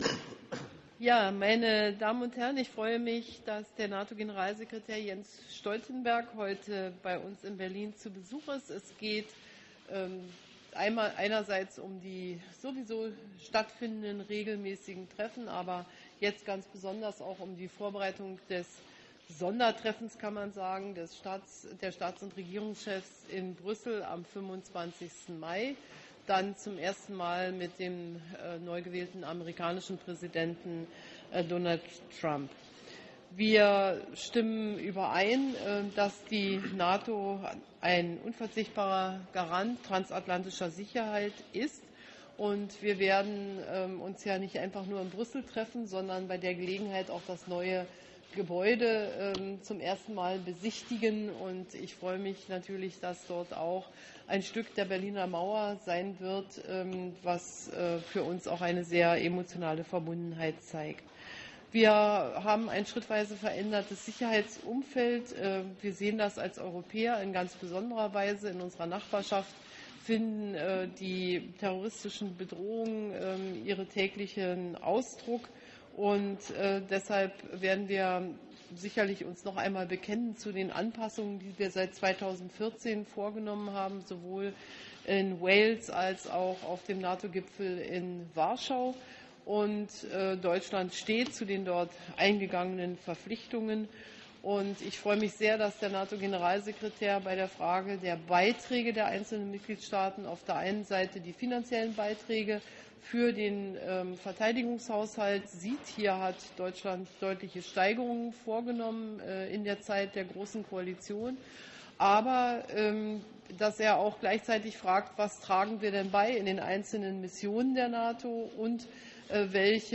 Joint press point